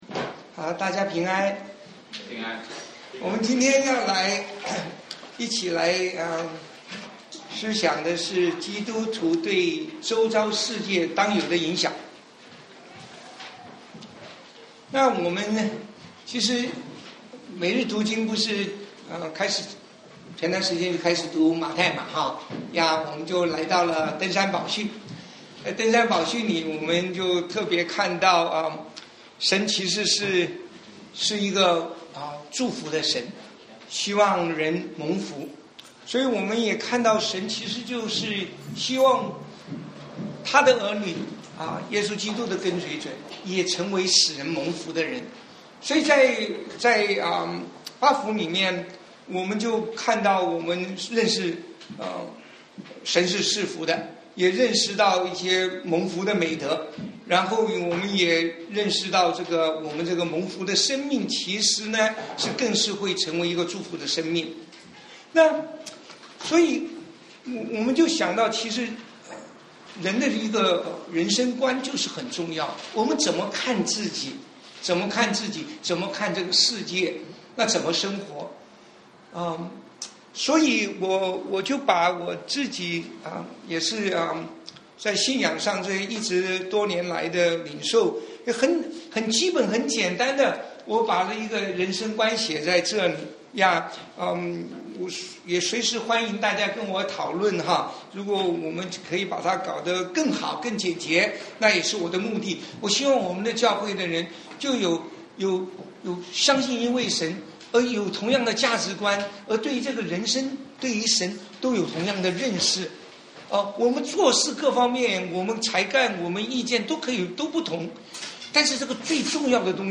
Play Audio 聽講道錄音 基督徒對周遭世界當有的影響 中心思想 : 讓我們成為使人蒙福的人 馬太福音 5:13-16 引言 : 一個蒙福與祝福的 人生觀 1.